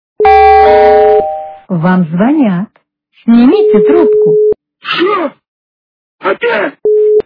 » Звуки » Смешные » Вам звонят - Шо, опять?
При прослушивании Вам звонят - Шо, опять? качество понижено и присутствуют гудки.